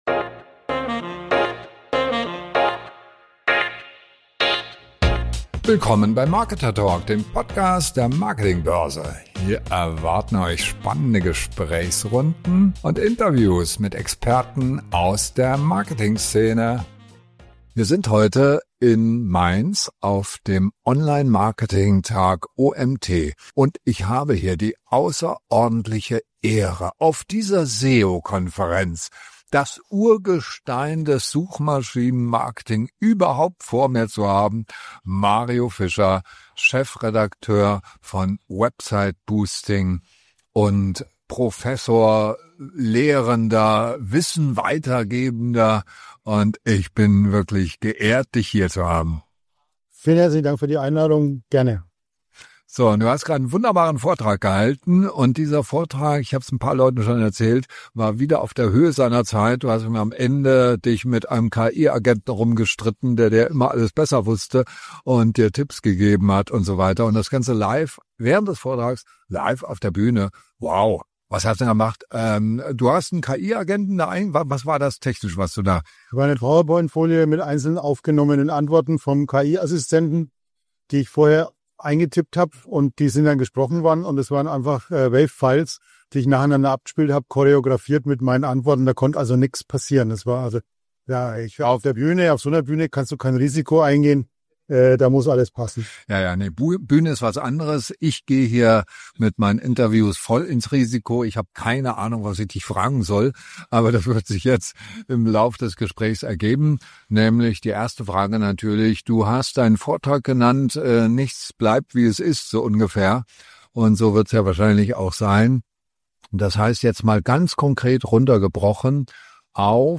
Suchmaschinen-Marketing steht vor einem Wandel. Nicht durch neue Regeln, sondern durch die Rückkehr zu den Basics. Ein Interview darüber, warum traditionelles SEO in der KI-Ära wichtiger denn je ist – und was Unternehmen konkret tun müssen.